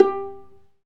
Index of /90_sSampleCDs/Roland L-CD702/VOL-1/STR_Viola Solo/STR_Vla Pizz